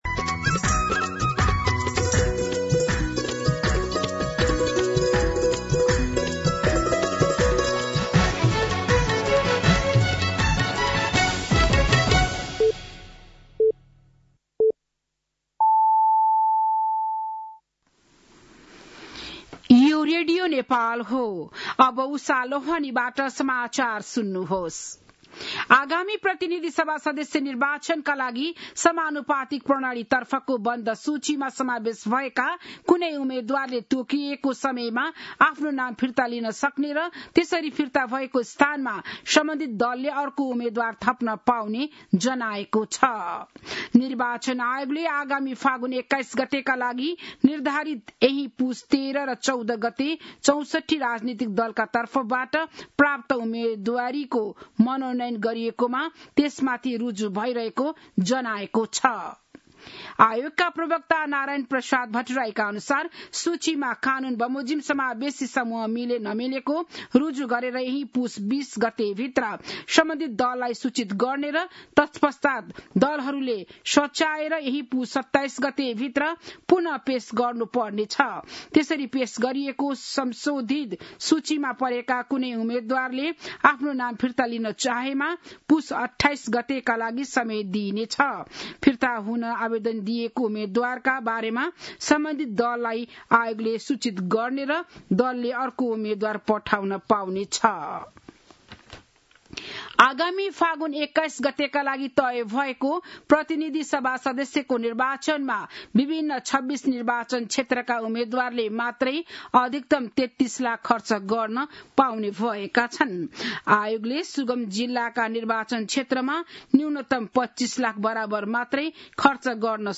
An online outlet of Nepal's national radio broadcaster
बिहान ११ बजेको नेपाली समाचार : २० पुष , २०८२